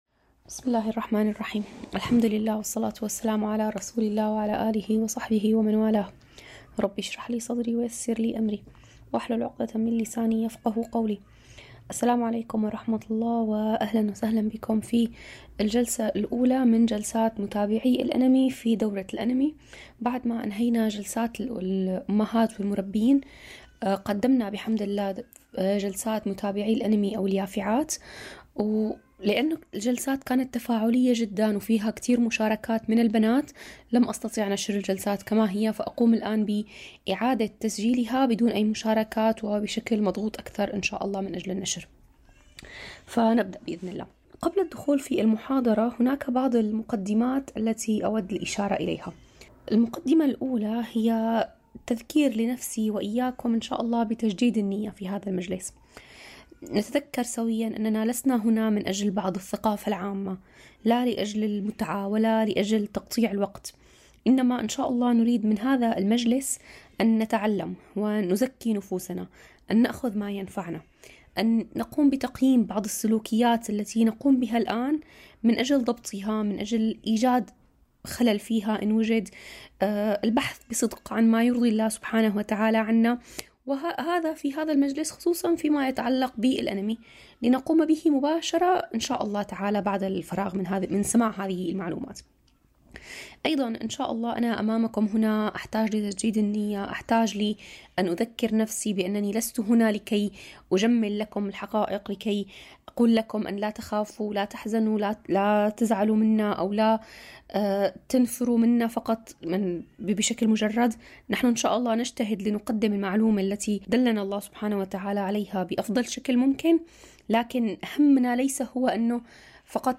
الجلسة الأولى لمتابعي الأنمي في دورة قدمت حوله عبر "لقلب حي".